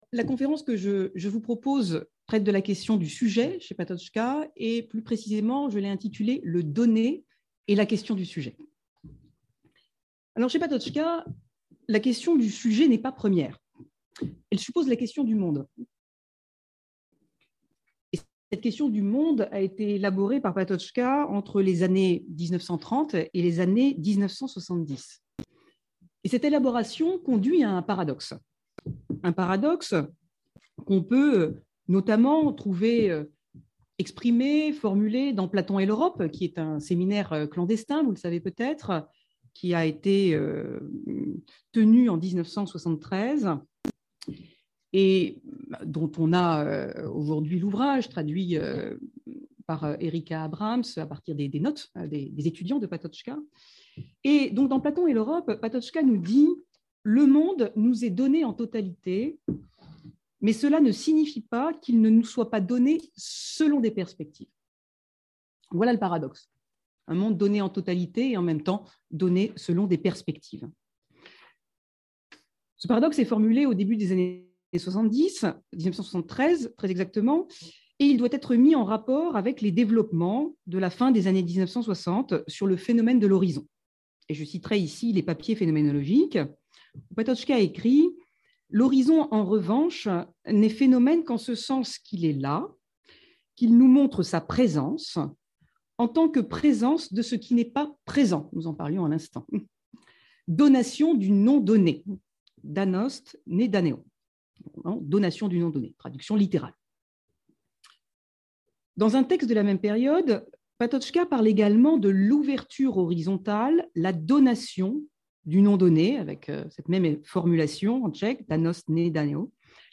conférence inaugurale